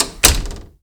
WOOD CLOSE.WAV